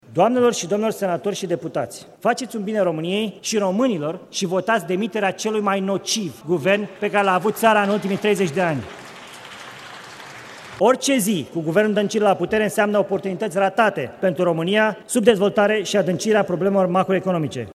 Senatorul PNL, Sorin Câţu, a cerut parlamentarilor să voteze – citez – demiterea „celui mai nociv guvern al României”.